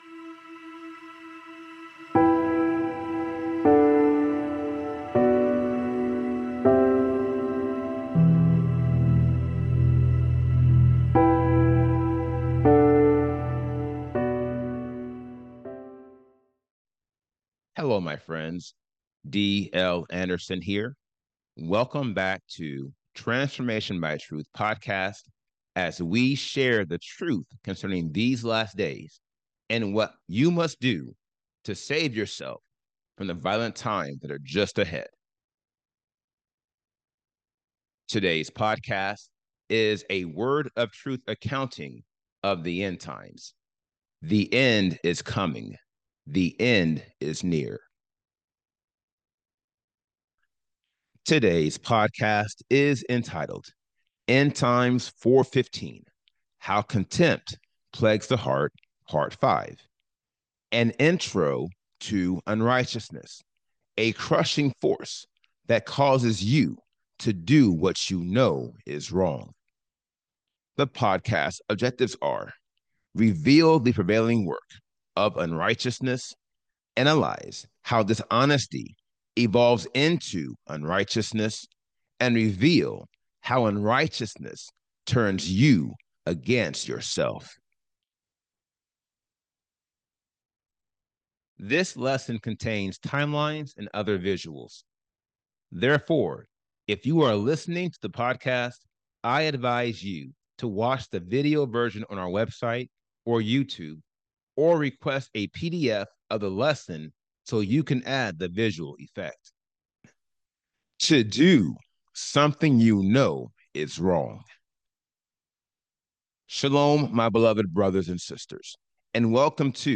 This podcast is a 400-level lecture dedicated to analyzing the 80 degrees of lawlessness and showing you how you can eliminate each one to obtain the Seal of Elohim. Its purpose is to reveal the prevailing work of unrighteousness, analyze how dishonesty evolves into unrighteousness, and reveal how unrighteousness turns you against yourself.